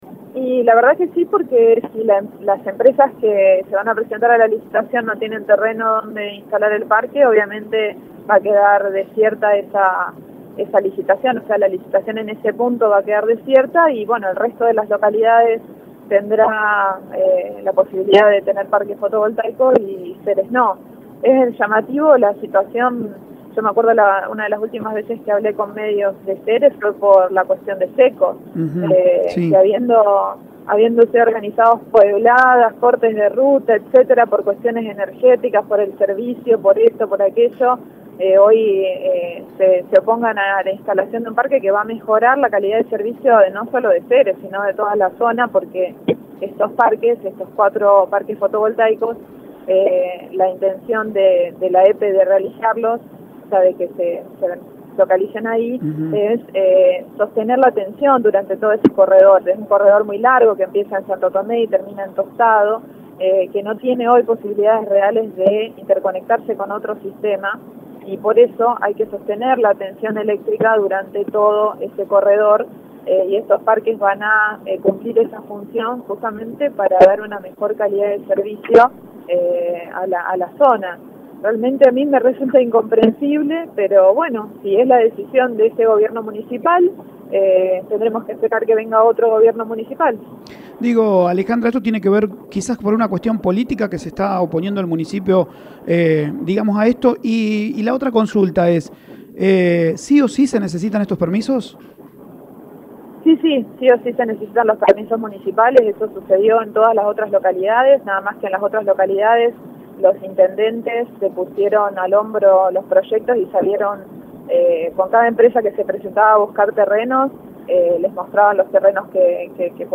Así lo hicieron saber el Concejal Gerardo Balzarini y la secretaria de Energía Verónica Geese en contacto con Radio EME